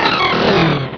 Cri d'Oniglali dans Pokémon Rubis et Saphir.